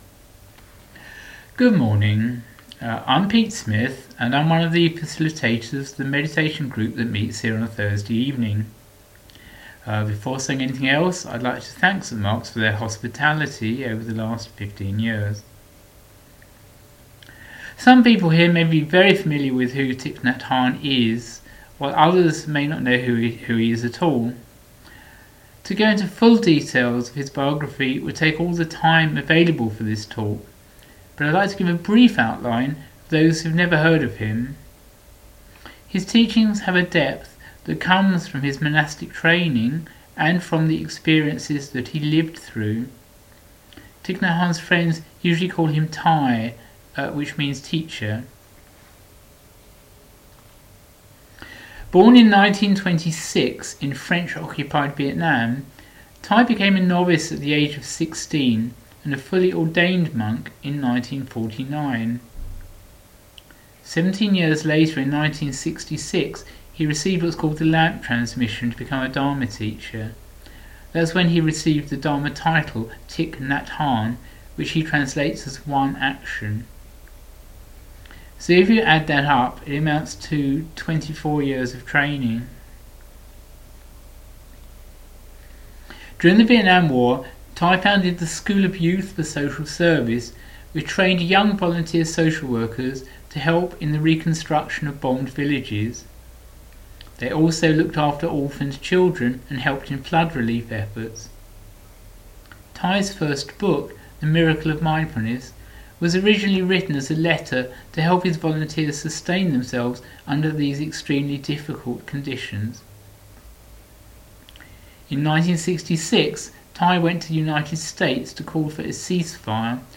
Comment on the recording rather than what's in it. talk-for-unitarians-edited.mp3